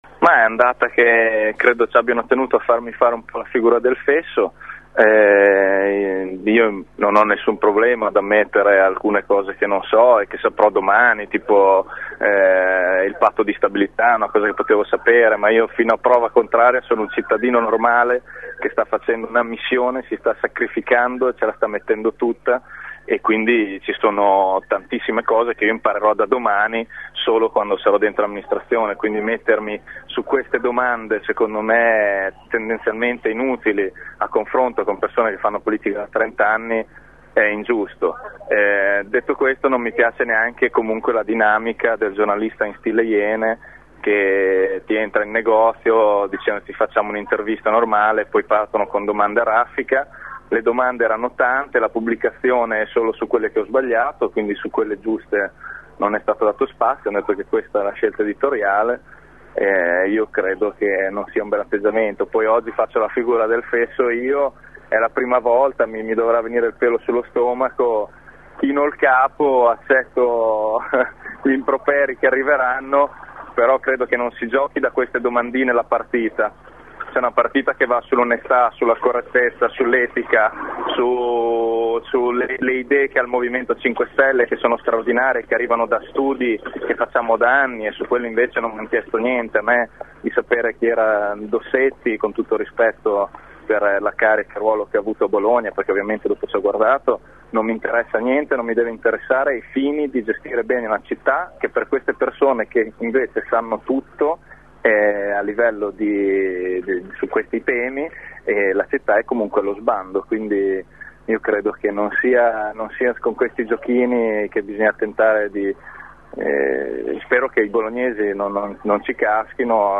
Bugani ai nostri microfoni non ci sta e replica piuttosto irritato: “mi hanno voluto far  fare le figura del fesso” e accusa i giornalisti del Corriere per lo stile da “Iene”, sempre a caccia dell’errore a tutti i costi.